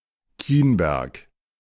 Kienberg (German pronunciationⓘ) is the name of several places:
De-Kienberg.ogg.mp3